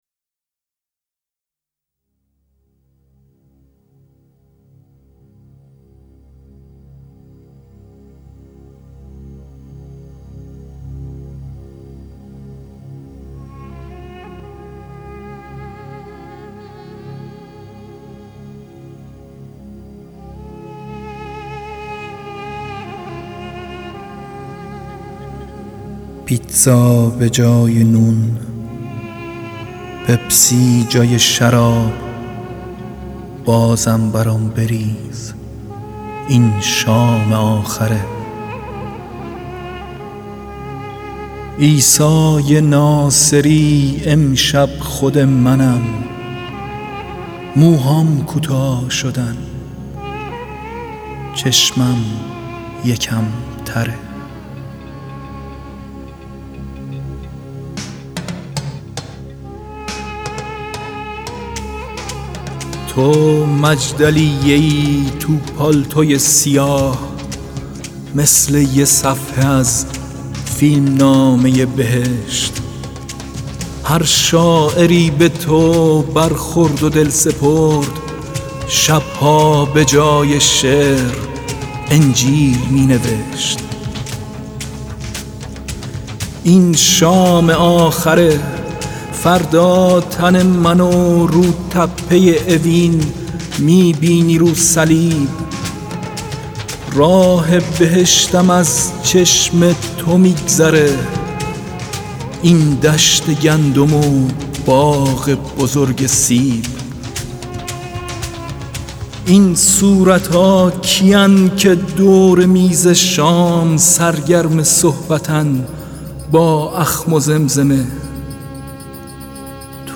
دانلود دکلمه شام آخر یغما گلرویی همراه با متن
گوینده :   [یغما گلرویی]